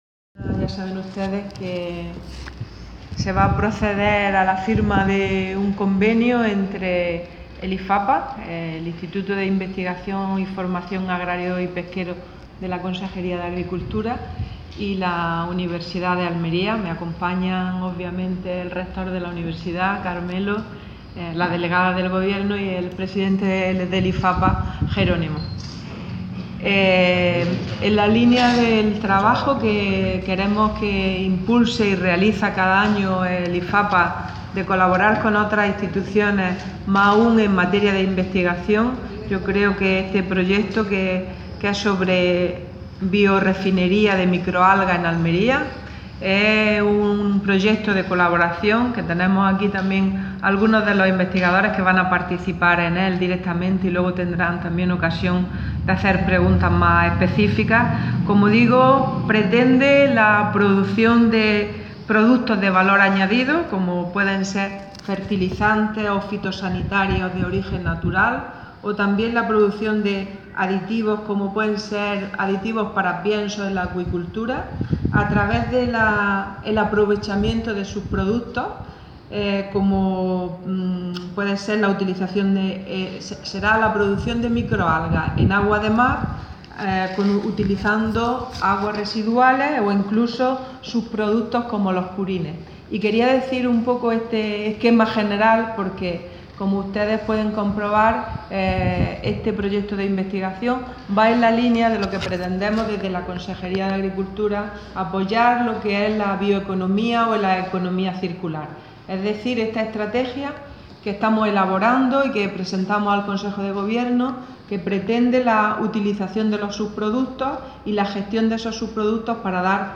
declaraciones consejera convenio